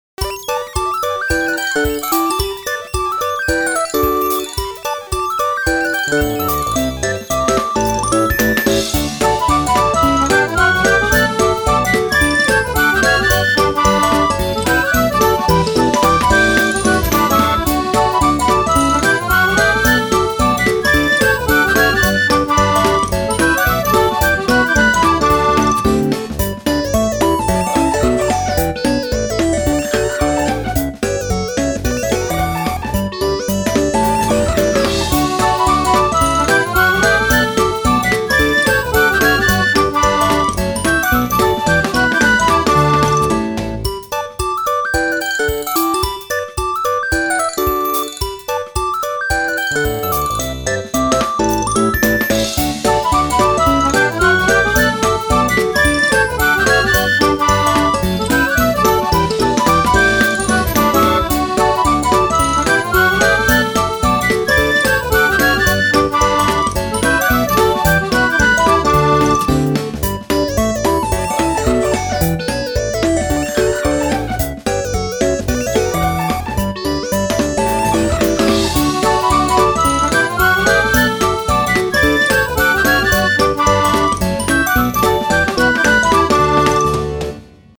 可愛い女の子がころころニコニコ転がってるイメージです。